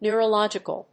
音節neu・ro・log・i・cal 発音記号読み方/n(j)`ʊ(ə)rəlάdʒɪk(ə)lnj`ʊərəlˈɔdʒ‐/ 形容詞